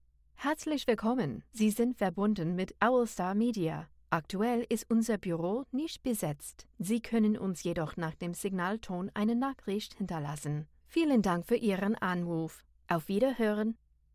• German Sample
Broadcast Quality LA Vocal Booth
• Neumann TLM 103
• Sennheiser MKH 416